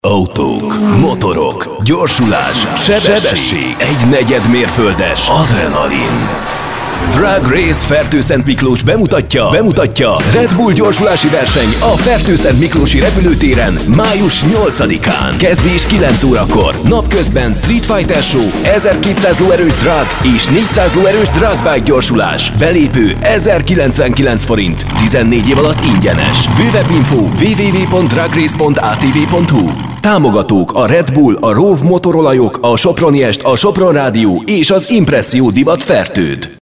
Sprecher ungarisch (Muttersprache) für Werbung, Voice over, Imagefilm, Industriefilm, TV, Rundfunk,
Sprechprobe: Werbung (Muttersprache):
Professionell voice over artist from Hungary.